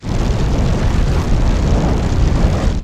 sfx_skill 10_2.wav